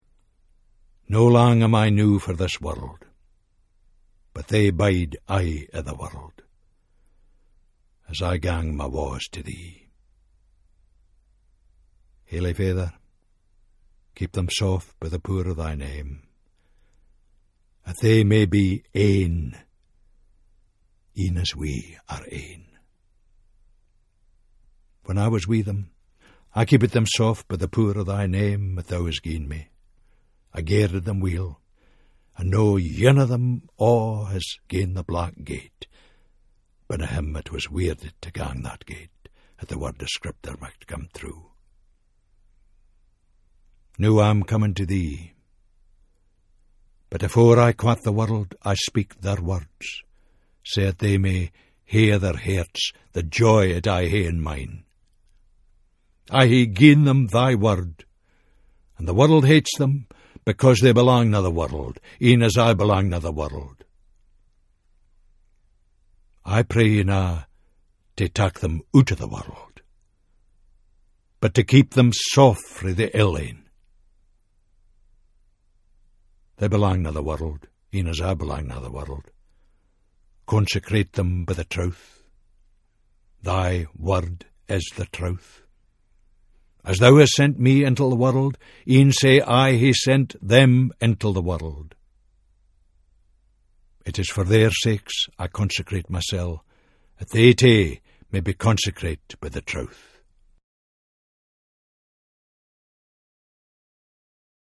In the recordings of the Scots New Testament, Tom Fleming brings the well known stories of the gospels to life in a distinct Scots voice.